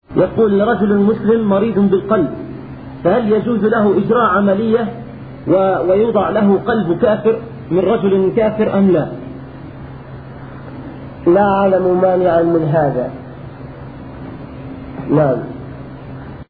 ------------ من شريط أسئلة من لندن